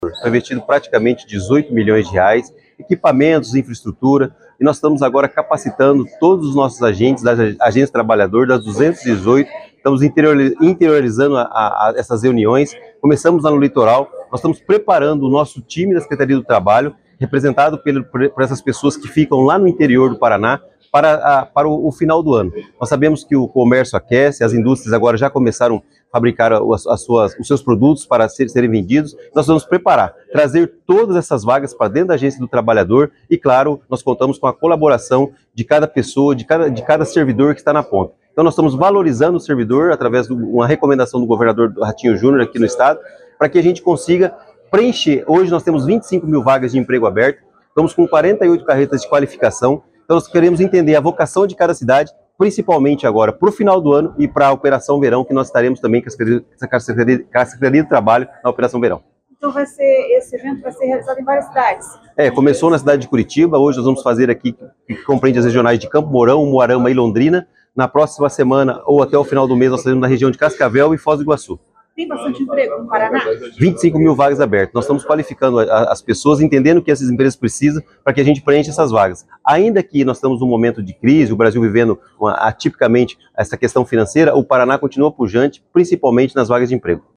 Ouça o que diz o secretário do Trabalho do Paraná, Paulo Rogério do Carmo.